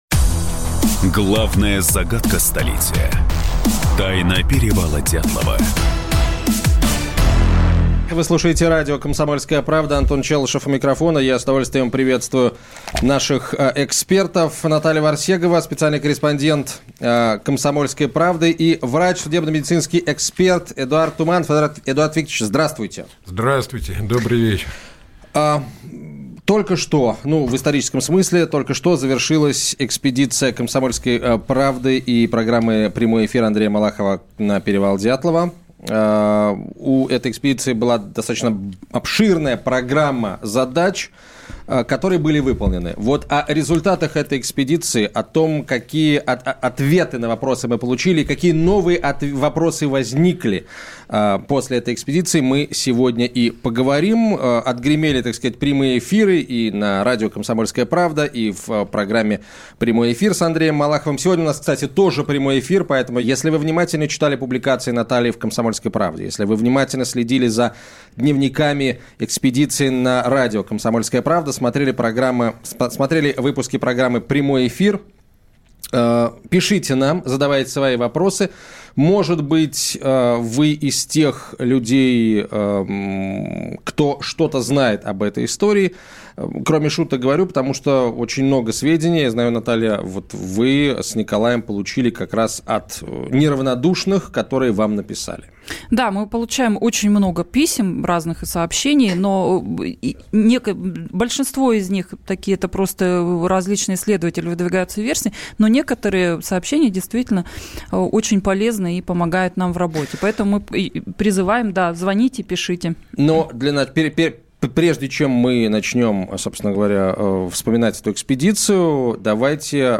Aудиокнига Эксклюзив! Экспедиция закончилась.